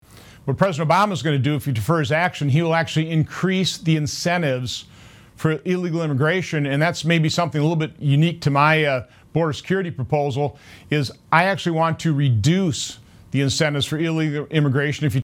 Senator Johnson gave these answers during an interview on Wednesday, Nov. 12, with WBAY-TV.